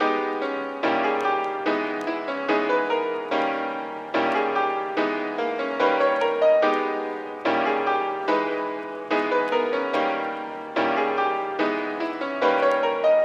刷圈
描述：在145 bpm的情况下，一个长长的爵士刷圈
Tag: 音响 刷子 滚筒 爵士